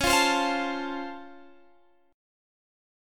Db+M7 Chord
Listen to Db+M7 strummed